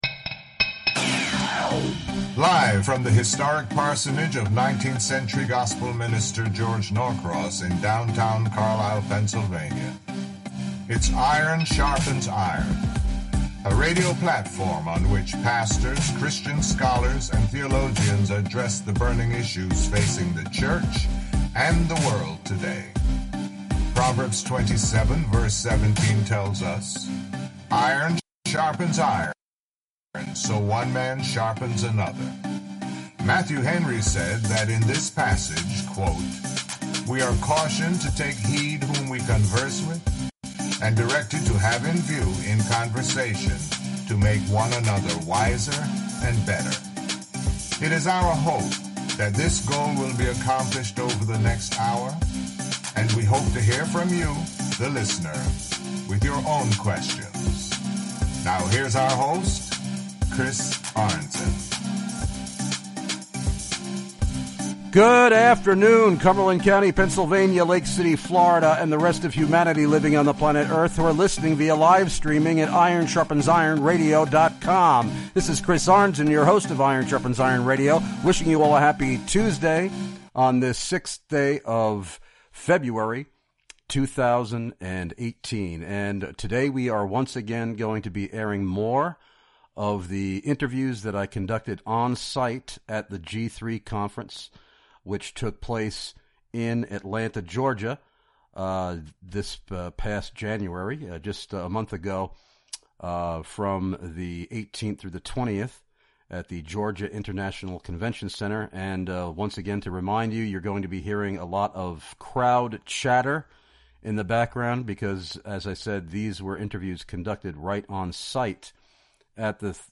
Interviews recorded LIVE at the 2018 G3 CONFERENCE!!!
These interviews were conducted on-site from the Iron Sharpens Iron Radio booth in the exhibition hall of the Georgia International Convention Center in Atlanta.